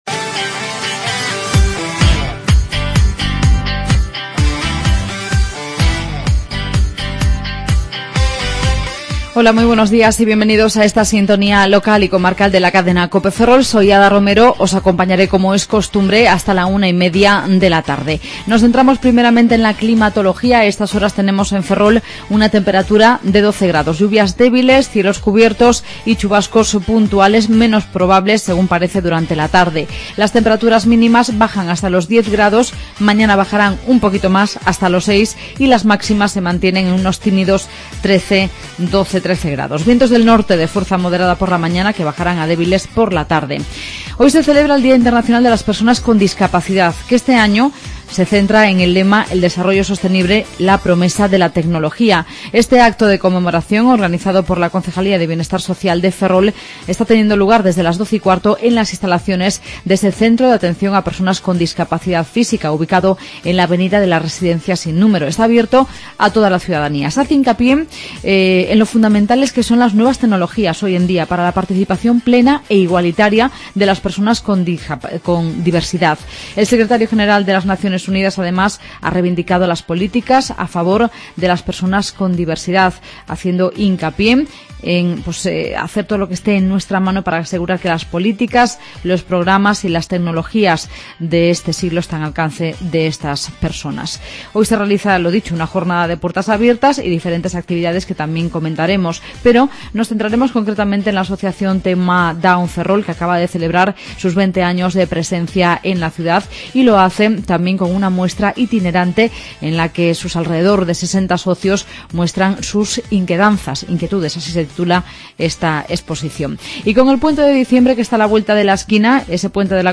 Redacción digital Madrid - Publicado el 03 dic 2014, 13:51 - Actualizado 14 mar 2023, 00:36 1 min lectura Descargar Facebook Twitter Whatsapp Telegram Enviar por email Copiar enlace Información de Ferrolterra, Eume y Ortegal. Hoy nos centramos en el día Internacional de las Personas con Discapacidad y entrevistamos a la Asociación Teima Down Ferrol